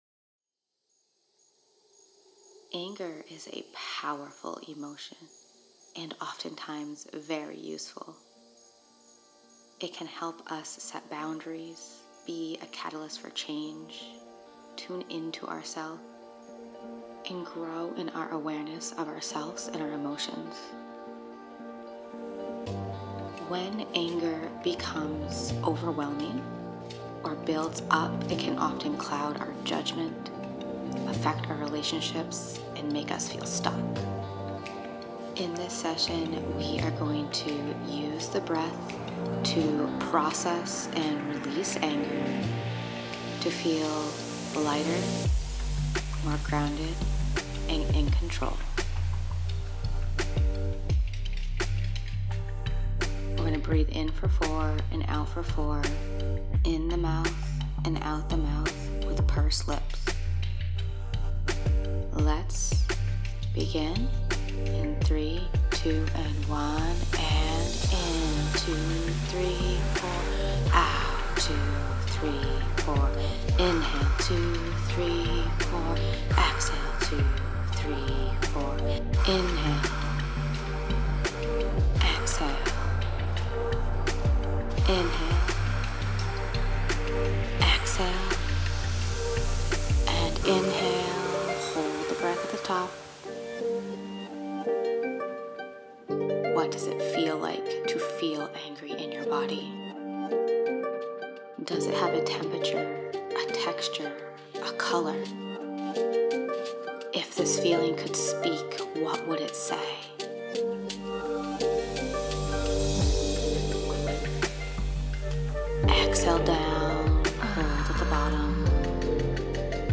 Heart-centered professionally guided Breathwork sessions to calm, clear, and energize.
Easy to follow breaths with intuitively chosen music.